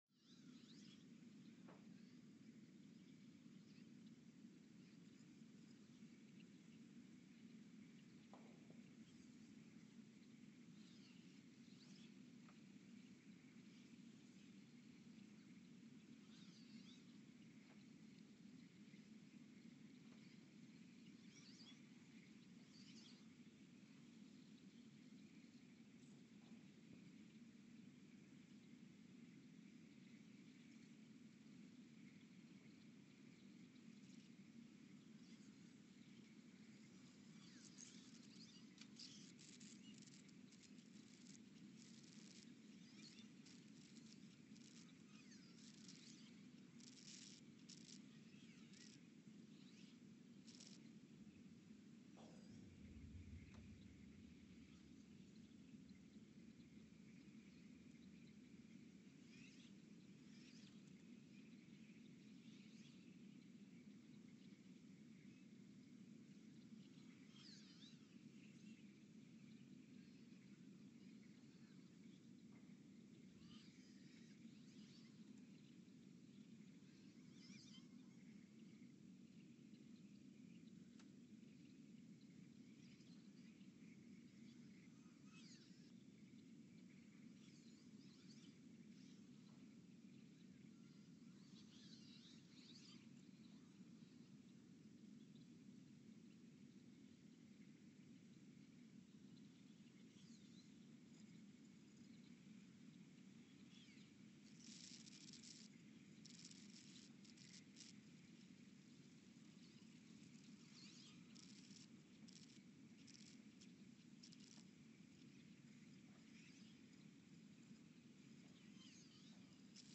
The Earthsound Project is an ongoing audio and conceptual experiment to bring the deep seismic and atmospheric sounds of the planet into conscious awareness.
Sensor : STS-1V/VBB Recorder : Quanterra QX80 @ 20 Hz
Speedup : ×900 (transposed up about 10 octaves)
Loop duration (audio) : 11:12 (stereo)